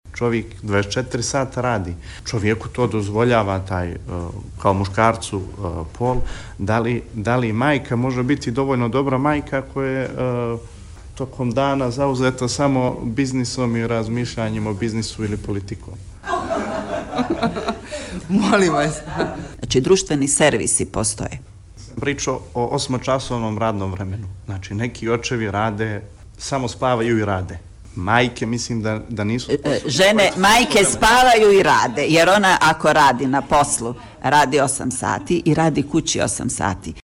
Maksim Vučinić, mladi poslanik Demokratskog fronta, je u Odboru za rodnu ravnopravnost Crne Gore tražio od poslanica da mu razjasne dilemu da li žene mogu biti dobre majke ako rade i bave se politikom. Pojašnjavala je poslanica Božena Jelušić kako je umjela.